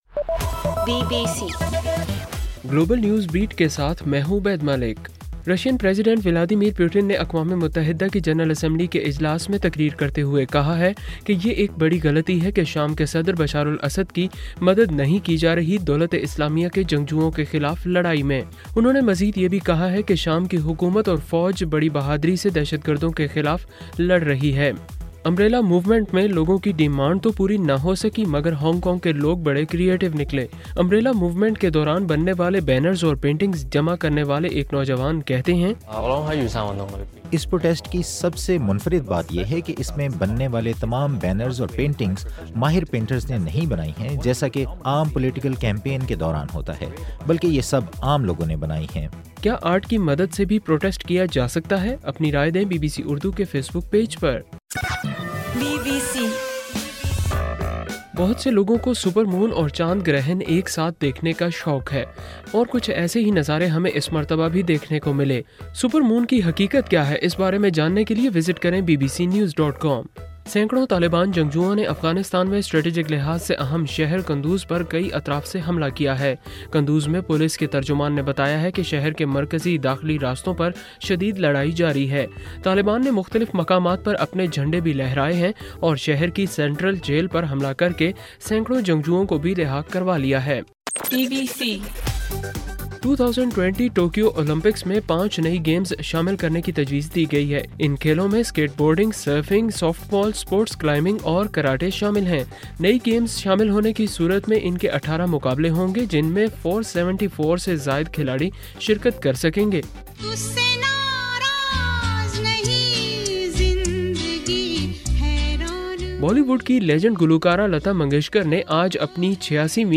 ستمبر 28: رات 12 بجے کا گلوبل نیوز بیٹ بُلیٹن